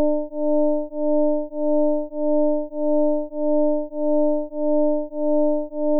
audacityの正弦波ジェネレータで生成しやすいように、フーリエ級数展開しました。